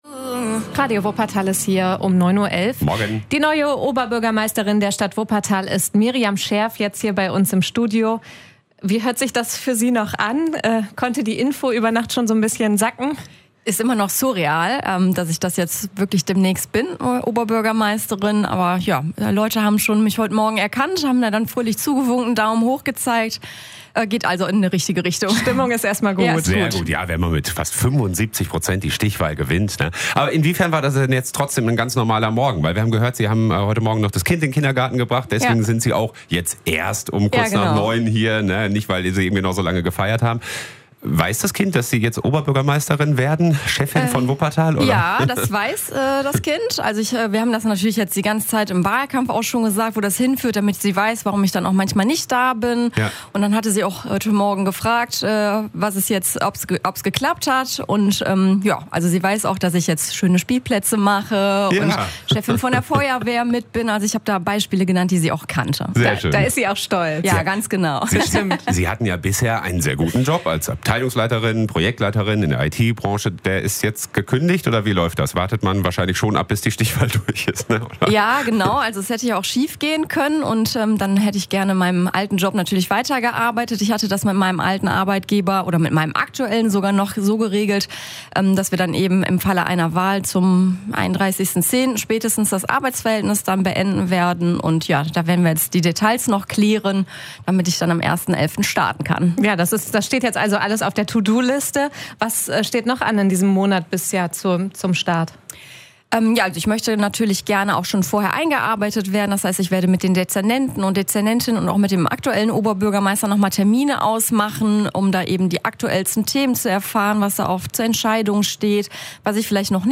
Miriam Scherff live in der Morningshow
Am Morgen nach der Stichwahl kommt die neu-gewählte Oberbürgermeisterin Miriam Scherff von der SPD zu uns ins Studio. Mit uns spricht sie über die Feier am Abend, über die ersten Interviews, und die neue Aufgabe, die vor ihr liegt. Hört euch hier das ganze Interview an.